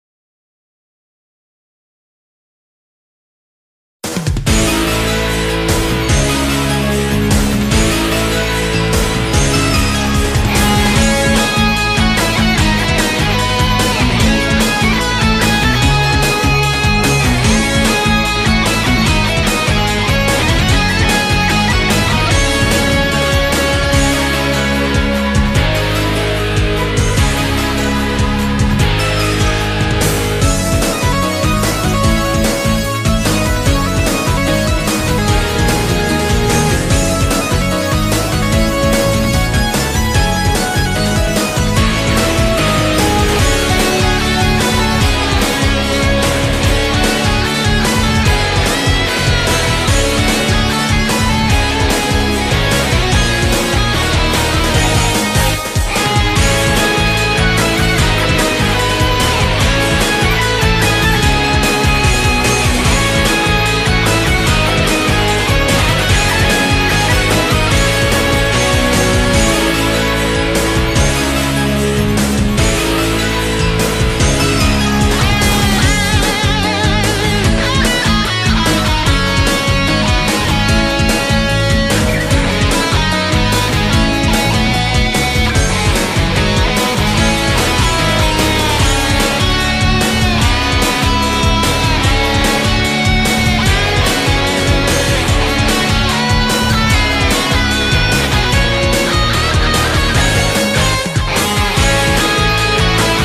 amo este soundtrack
da unas vibras alegres y emocionales a la vez^^